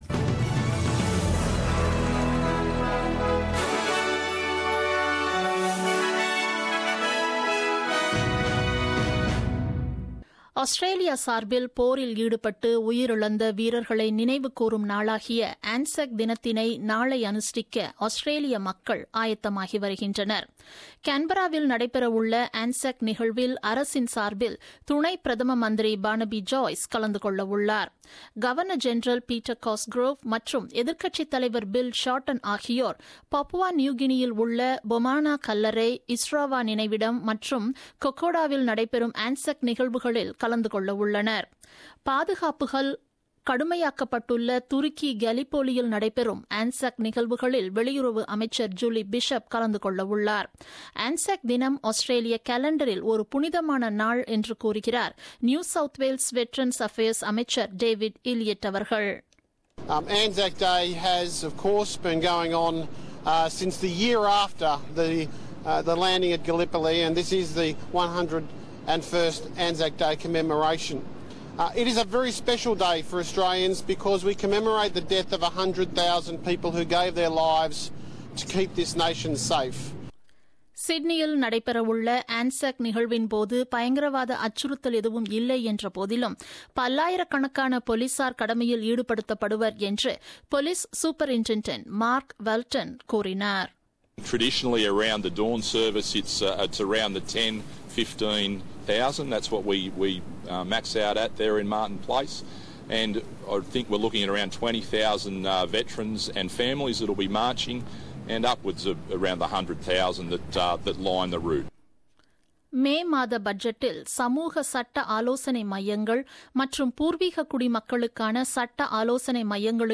The news bulletin broadcasted on 24th April 2017 at 8pm.